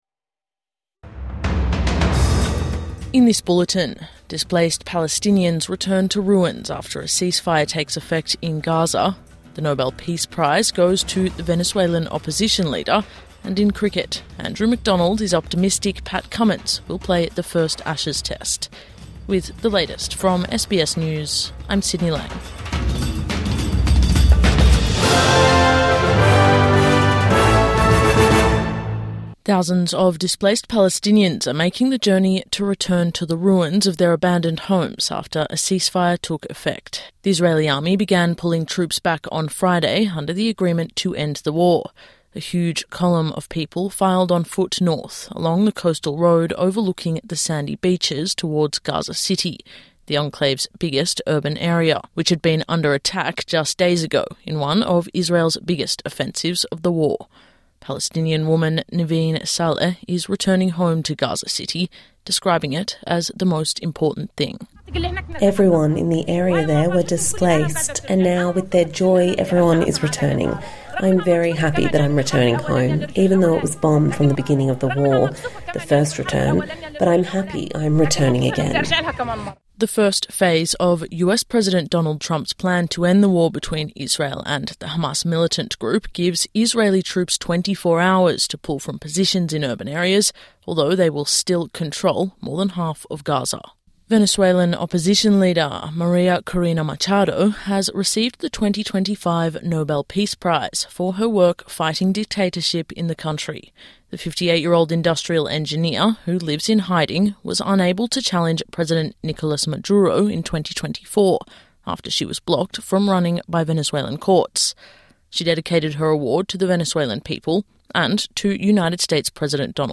Thousands of displaced Palestinians return home following ceasefire | Morning News Bulletin 11 October 2025